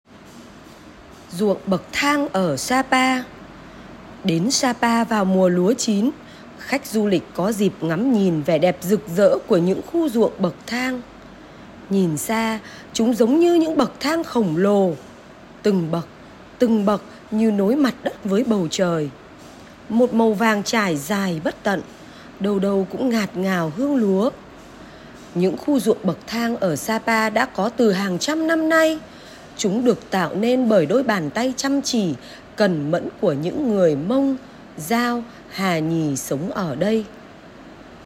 Sách nói | RUỘNG BẬC THANG Ở SA PA - TIẾNG VIỆT 1